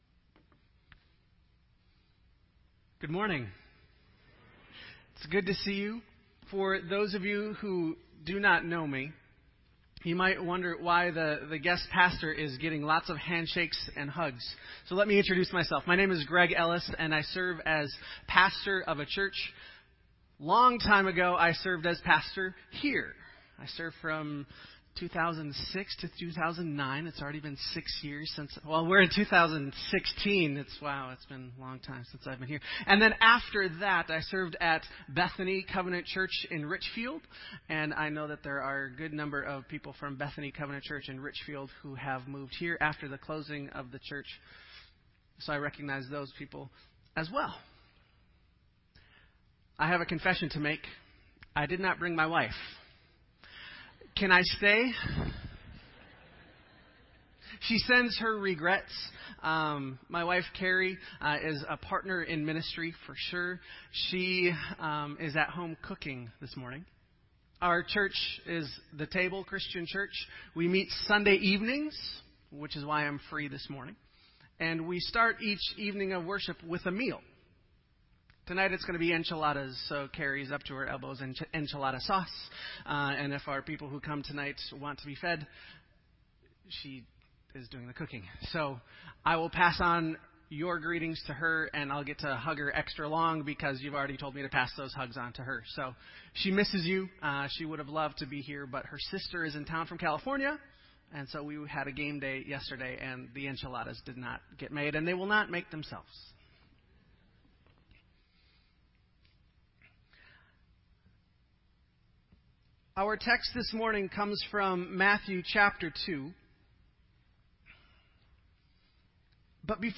This entry was posted in Sermon Audio on January 4